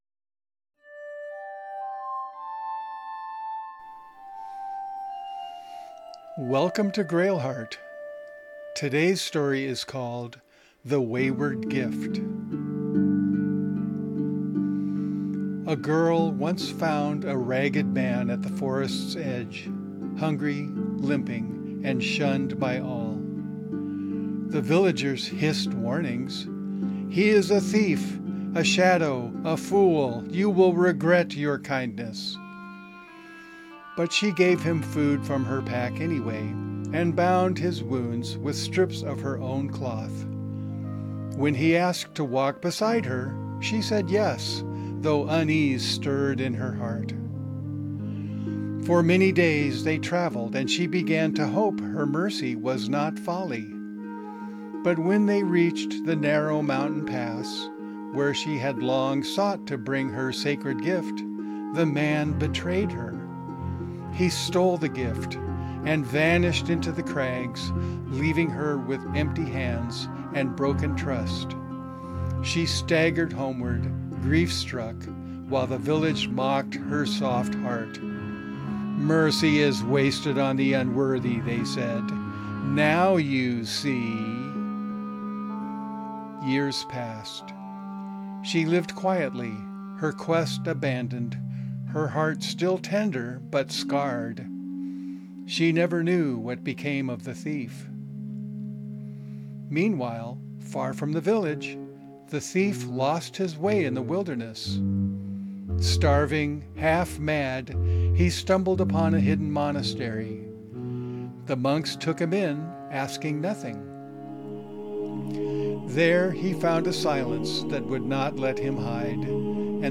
Read aloud